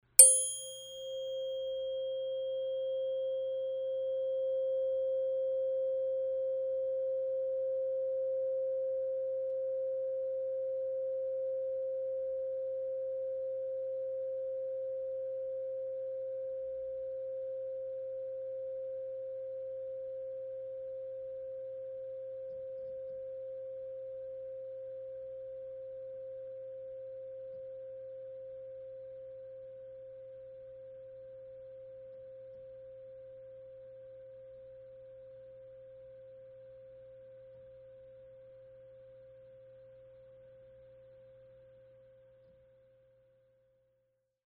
BioSonics DNA 528 Hz Solfeggio Stimmgabel "Mi"